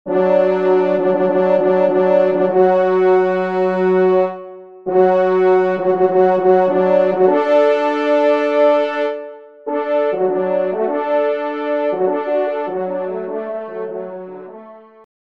3ème Trompe